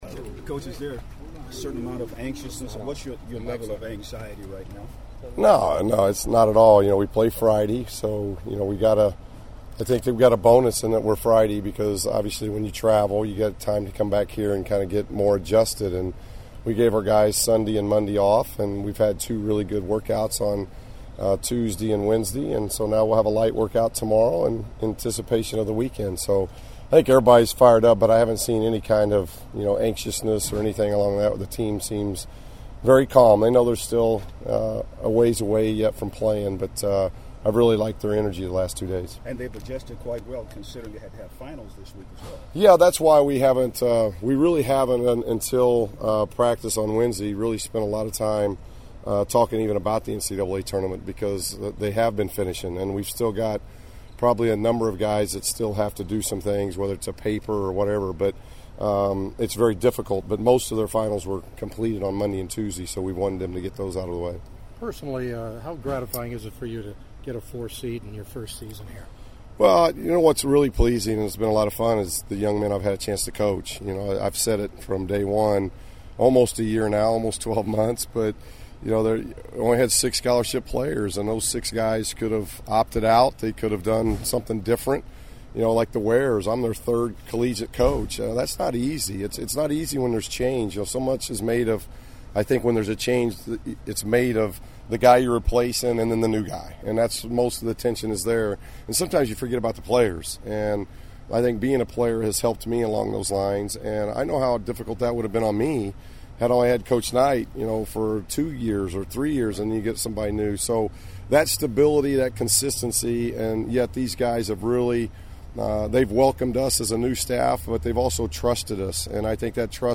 I also got to interview UCLA head coach Steve Alford and his Junior guard Norman Powell before the 4th seeded Bruins boarded their bus for San Diego where they’ll take on Tulsa on Friday night.